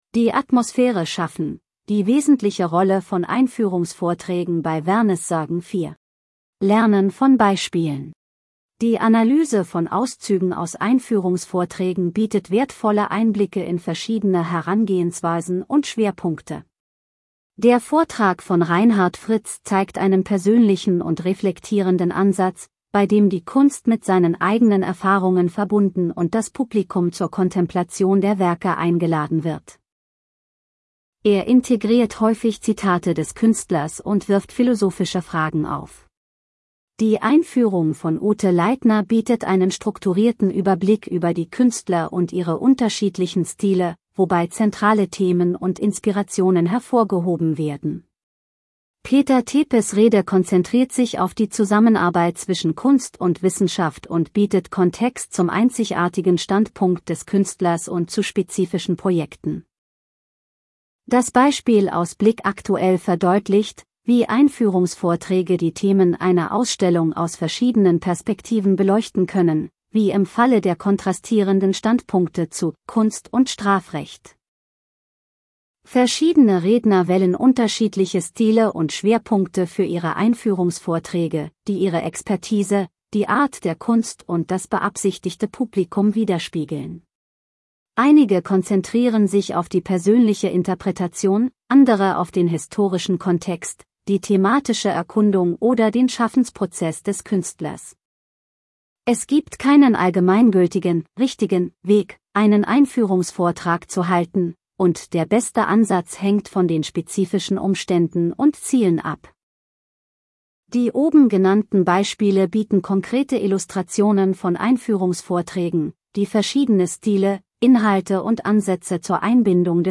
der obige Text wird von unserer virtuellen Stimme lLuisa gelesen